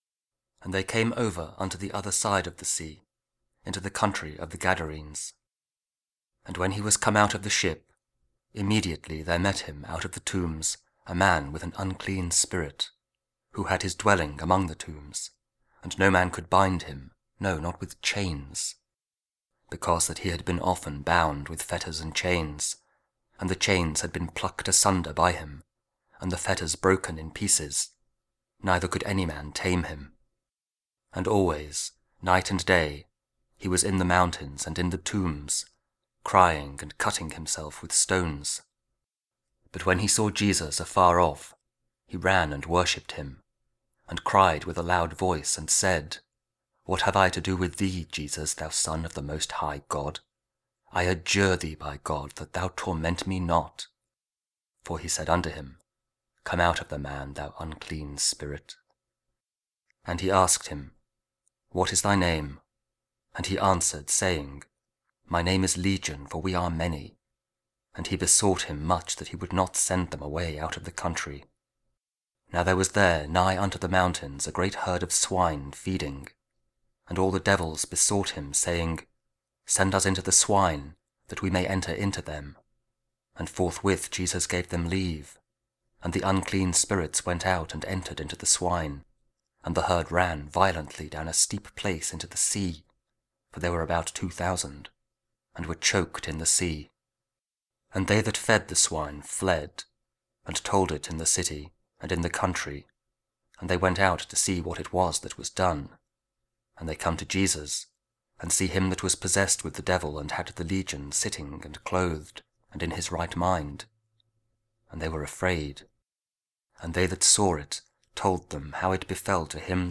Mark 5: 1-20 – Week 4 Ordinary Time, Monday (Audio Bible KJV, Spoken Word)